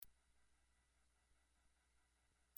16khz- 30 yrs old and younger